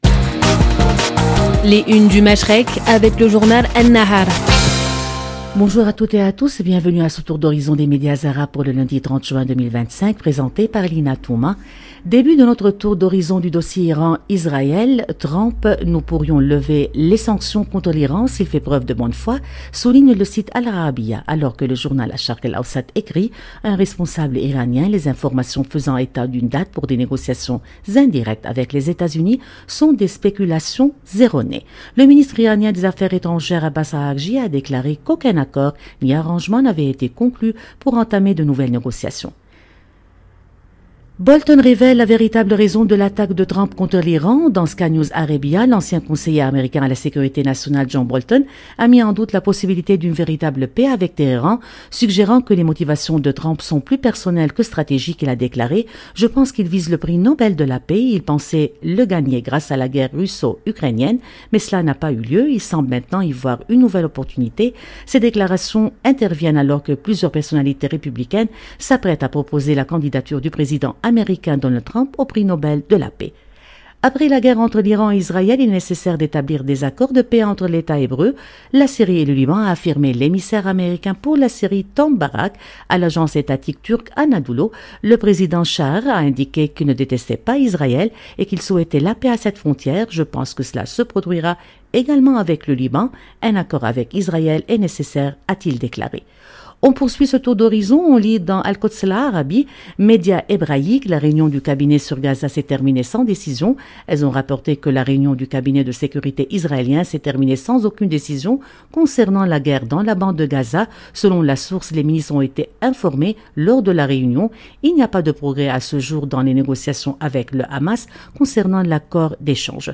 Chaque matin, Radio Orient vous propose, en partenariat avec le journal libanais An-Nahar, une revue de presse complète des grands titres du Moyen-Orient et du Golfe. À travers des regards croisés et des analyses approfondies, cette chronique quotidienne offre un décryptage rigoureux de l’actualité politique, sociale et économique de la région, en donnant la parole aux médias arabes pour mieux comprendre les enjeux qui façonnent le Machrek.